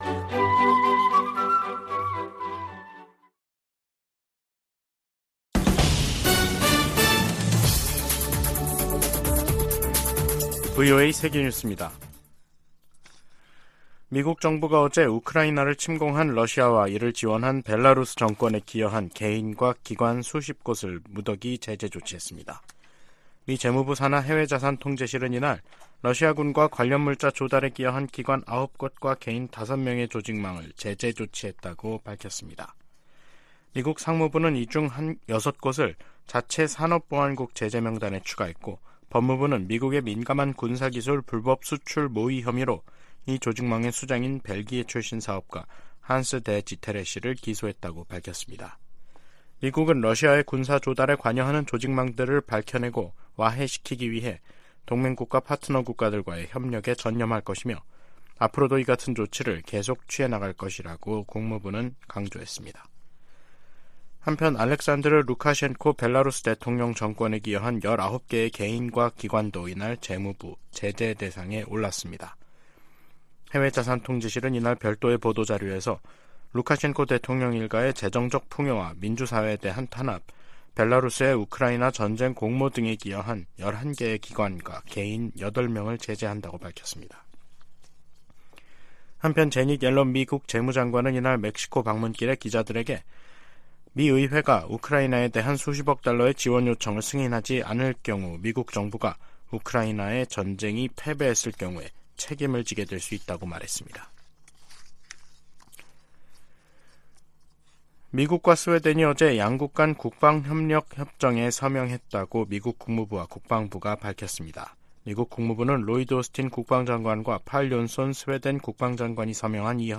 VOA 한국어 간판 뉴스 프로그램 '뉴스 투데이', 2023년 12월 6일 2부 방송입니다. 유엔총회가 북한 핵실험을 규탄하고 핵무기 폐기를 촉구하는 내용을 담은 결의 3건을 채택했습니다. 유럽연합(EU)이 북한과 중국, 러시아 등 전 세계의 심각한 인권 침해 책임자와 기관에 제재를 3년 연장했습니다. 북한 지도자가 딸 주애를 계속 부각하는 것은 세습 의지를 과시하기 위해 서두르고 있다는 방증이라고 한국 고위관리가 지적했습니다.